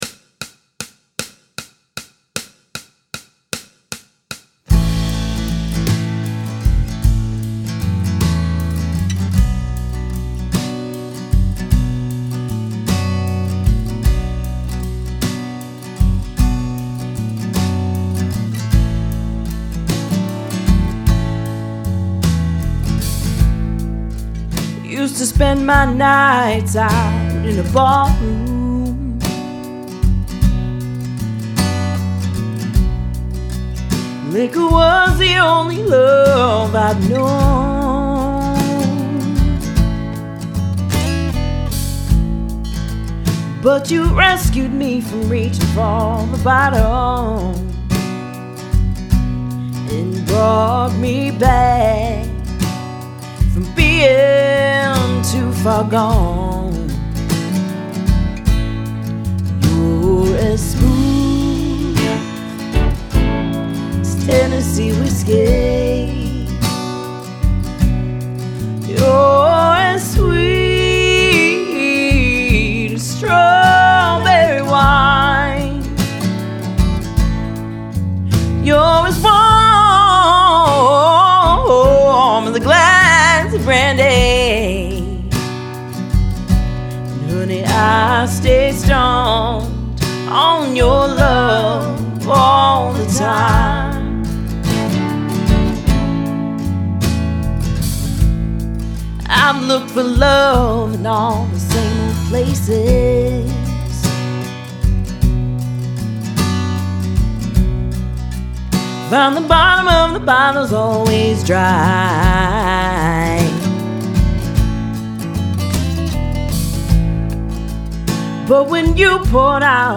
Backing jam track
we're in the key of A major with just A and Bm